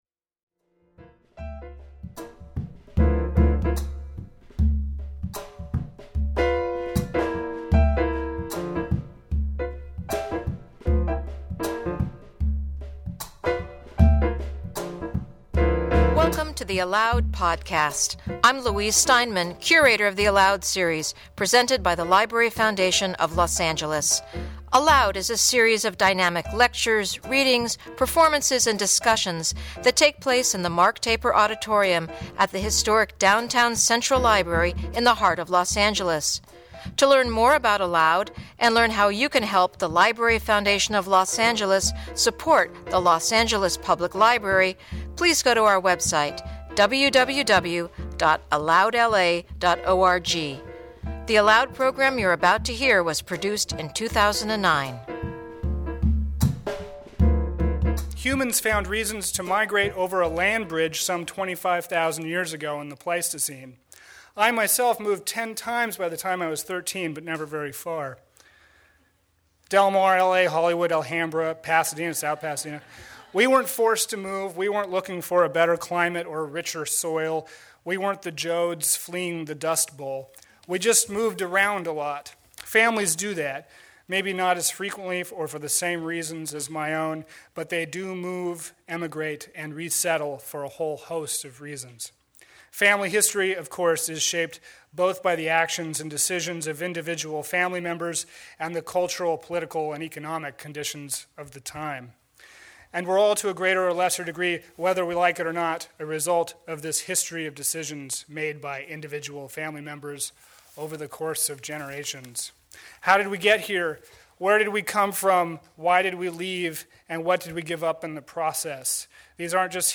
In conversation with journalist